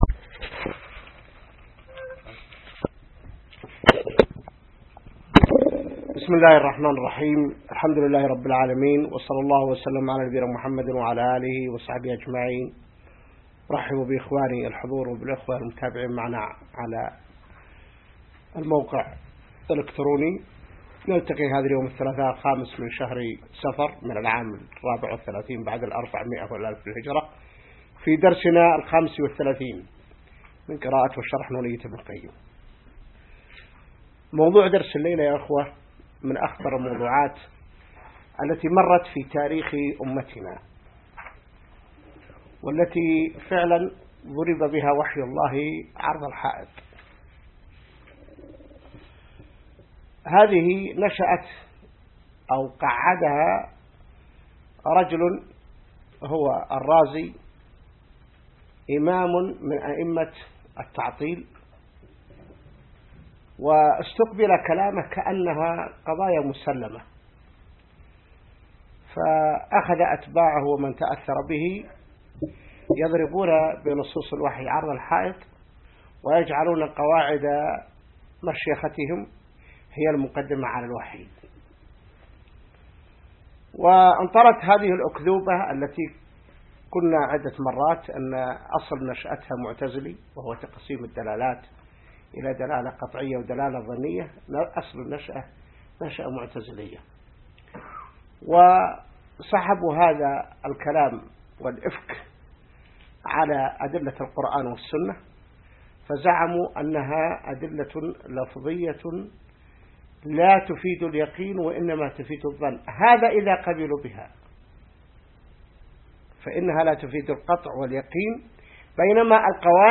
الدرس 35 من شرح نونية ابن القيم | موقع المسلم